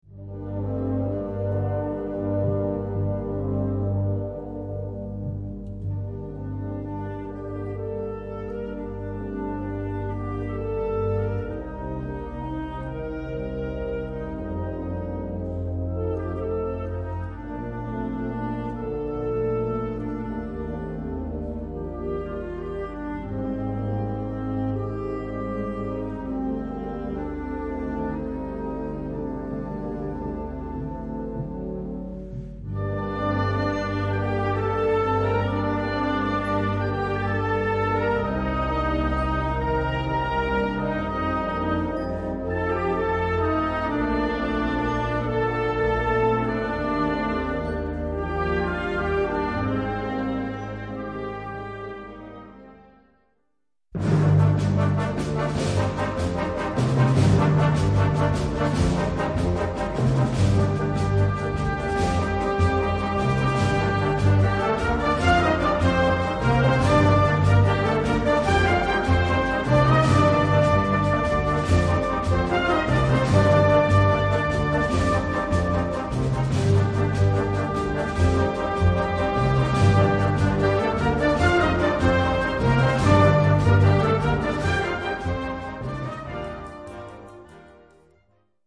Konzertante Blasmusik
Blasorchester